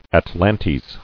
[at·lan·tes]